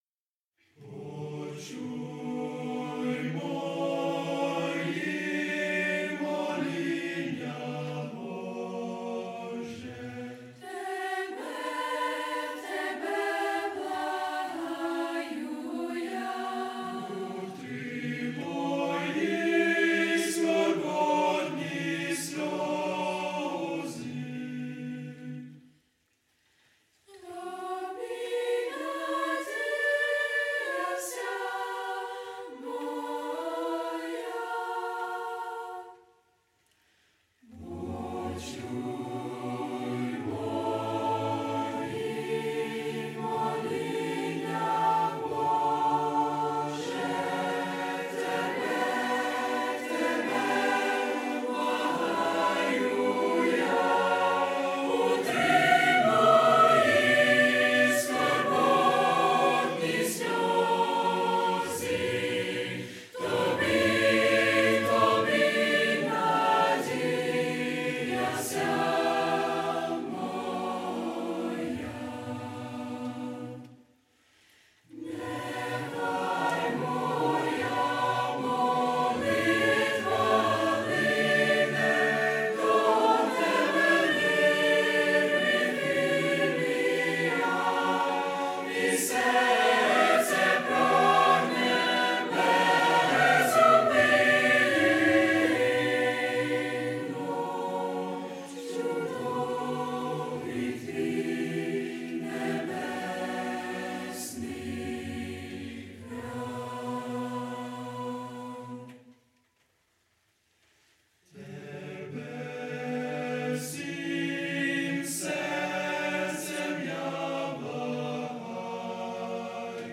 Пісні молодіжного хору | Sep 23, 2025